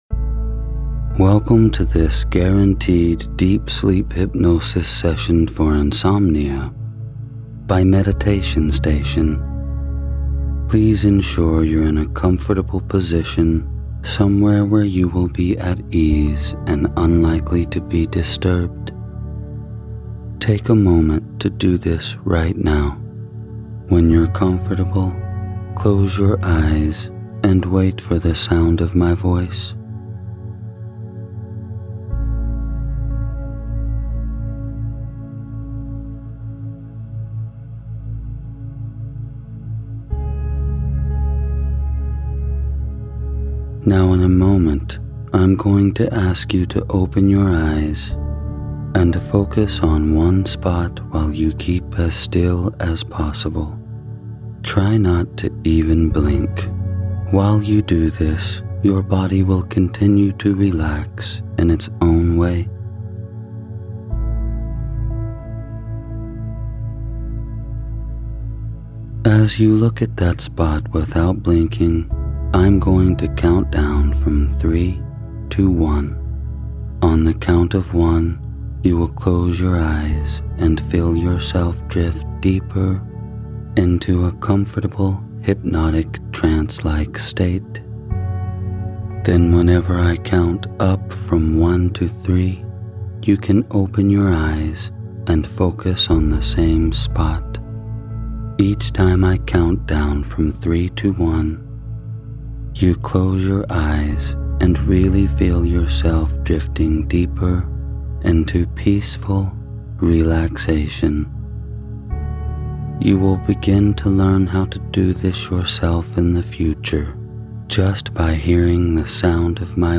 You're looking for a sleep hypnosis session that's all about falling asleep and resting. Insomnia can not keep you from a rest that you decided to meditate into you.